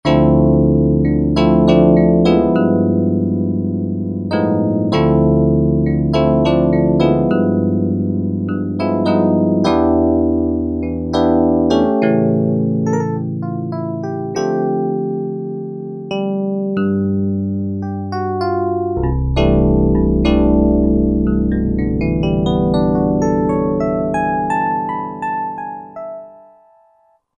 Roland S-760 audio demos
1 S-760E.Piano.mp3